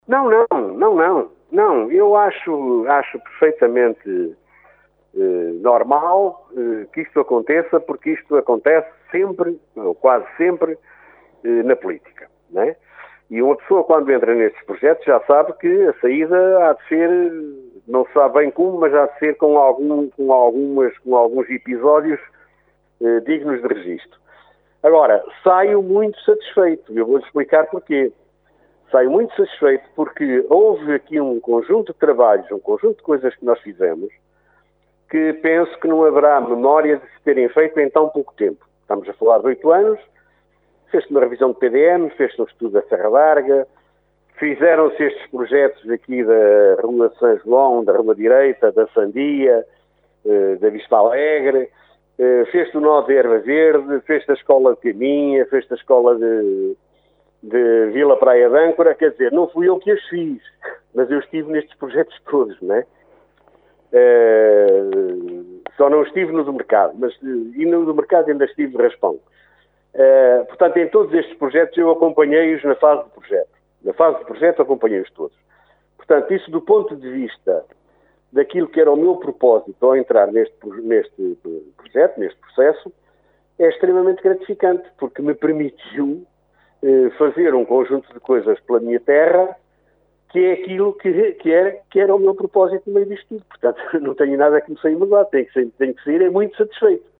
A confirmação foi feita ao Jornal C pelo próprio que, em entrevista, alegou falta de condições políticas para levar o mandato até ao fim.